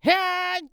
CK蓄力01.wav
CK蓄力01.wav 0:00.00 0:00.72 CK蓄力01.wav WAV · 62 KB · 單聲道 (1ch) 下载文件 本站所有音效均采用 CC0 授权 ，可免费用于商业与个人项目，无需署名。
人声采集素材/男2刺客型/CK蓄力01.wav